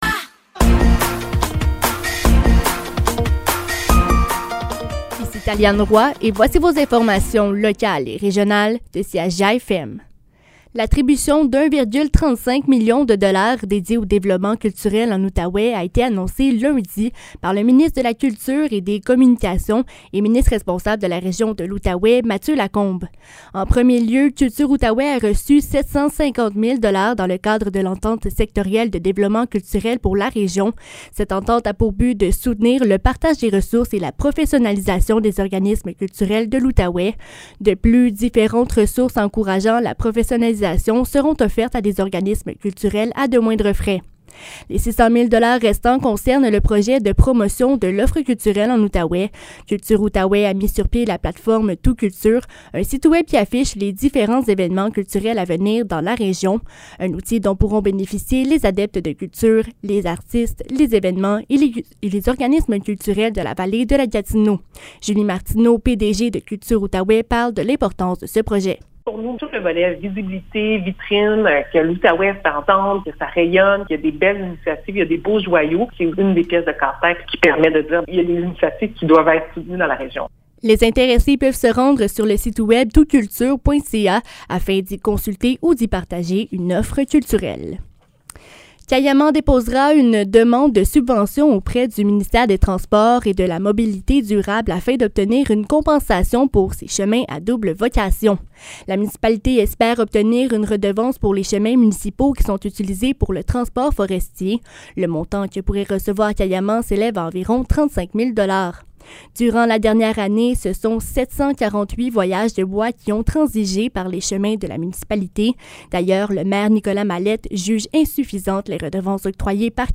Nouvelles locales - 3 mai 2023 - 15 h